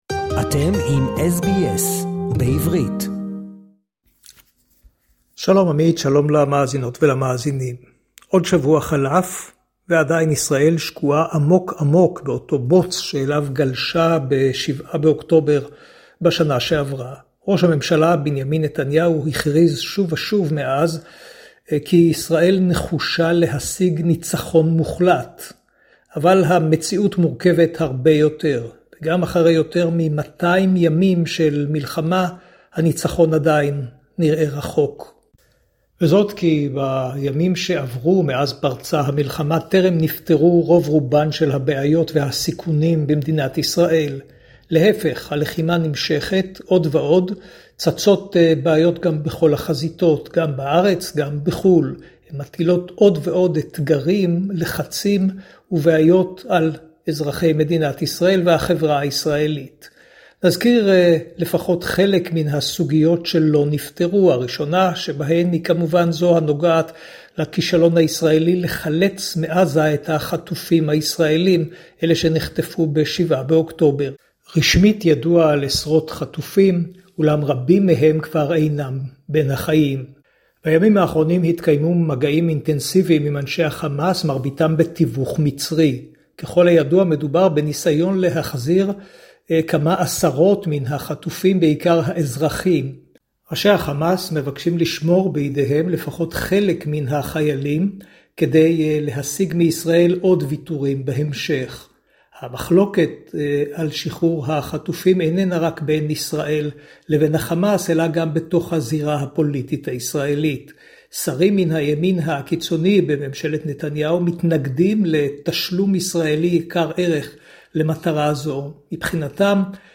reports to SBS Hebrew.